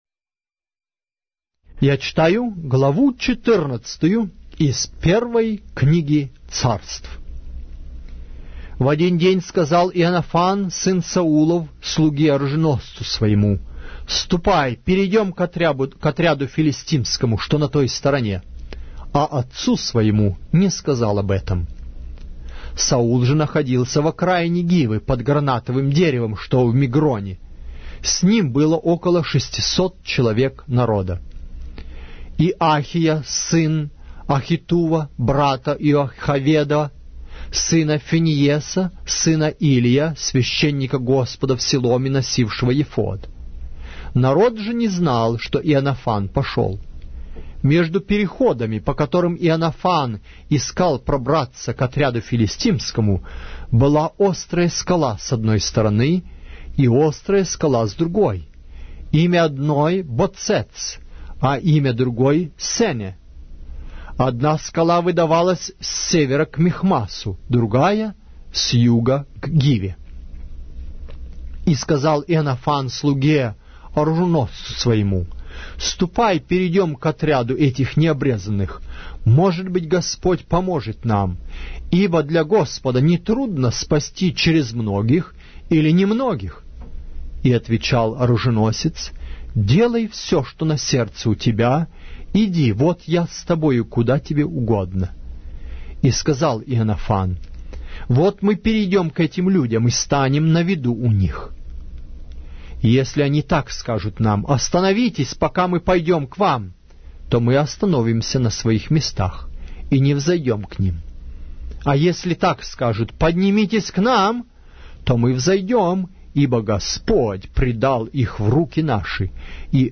Автор Аудио - Библия из аудиокниги "1-я Книга Царств".